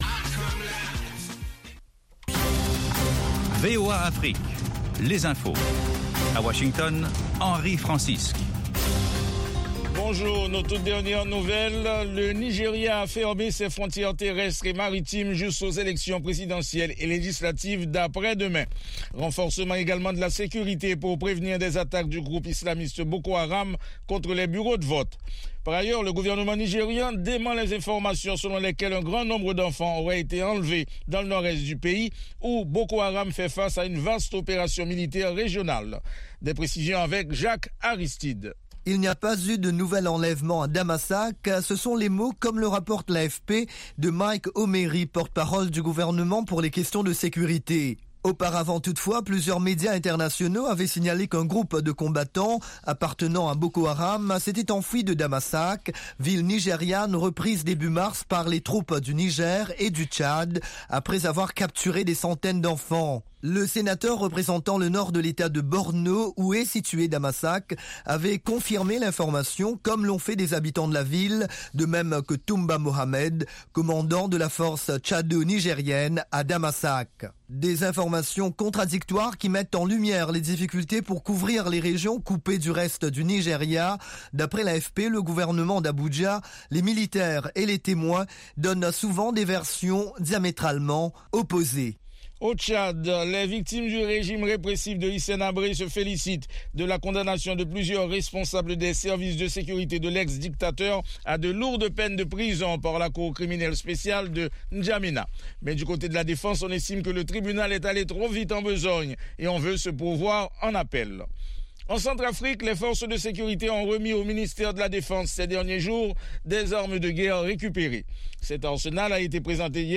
Bulletin
5 Minute Newscast